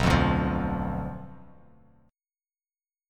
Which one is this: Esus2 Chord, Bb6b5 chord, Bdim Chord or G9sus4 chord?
Bdim Chord